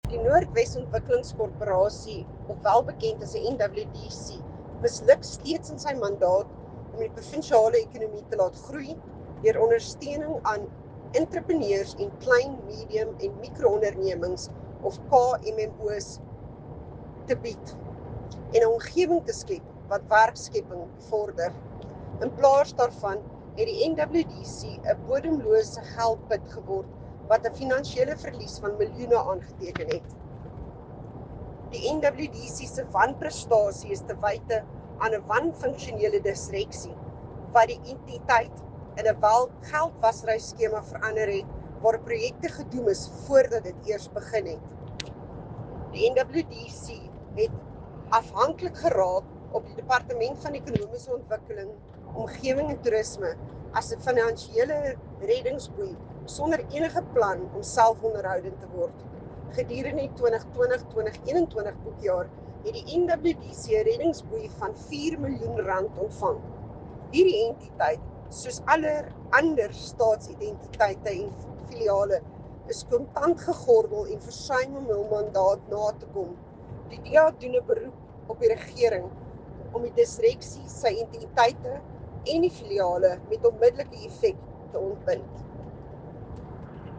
Note to Broadcasters: Find linked soundbites in
Afrikaans by Jacqueline Theologo MPL